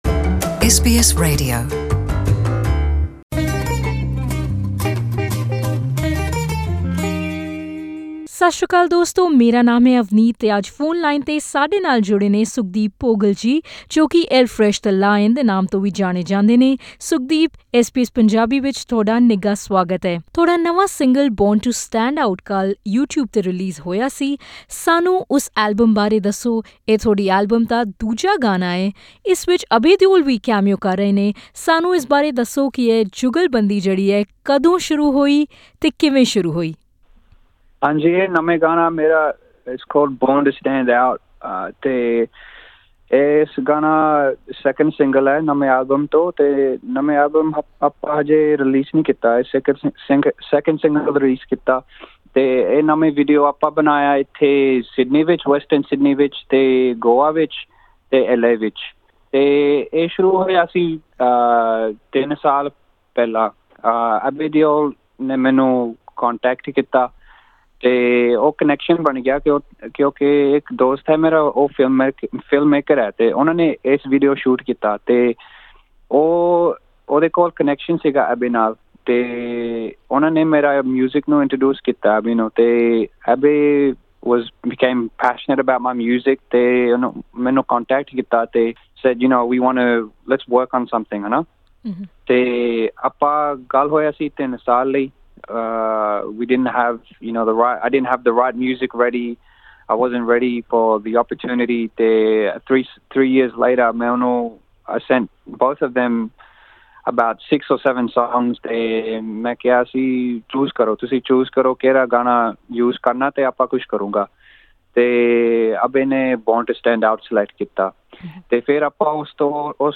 Speaking to SBS Punjabi , the Indian-Australian rapper said collaboration with Deol came through, because of a common friend who introduced the star to his music, three years ago.